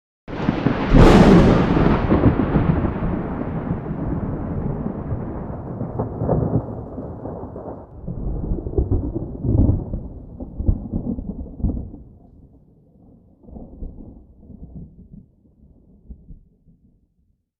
thunder-3.ogg